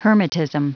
Prononciation du mot : hermitism